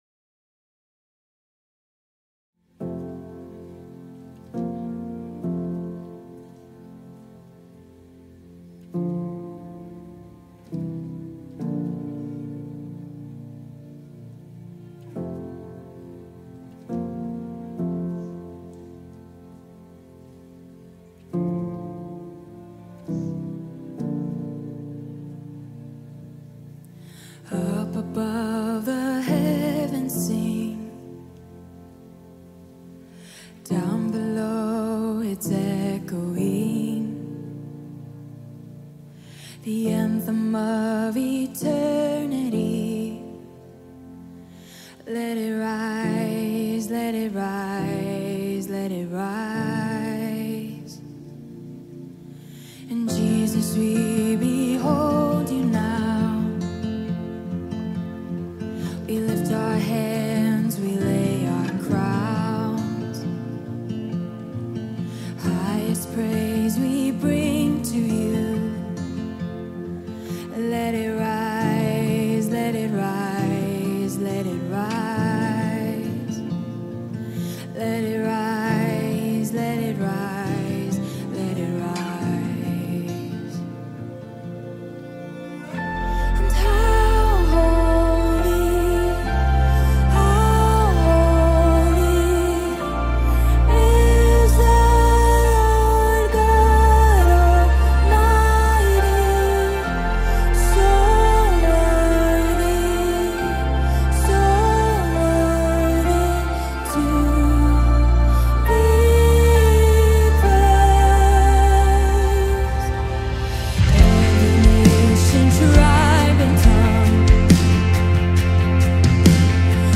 170 просмотров 110 прослушиваний 8 скачиваний BPM: 68